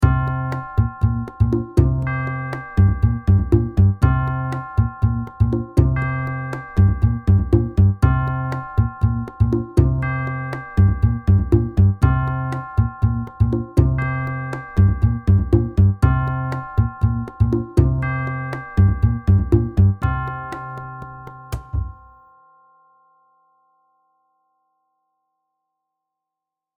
NO MELODY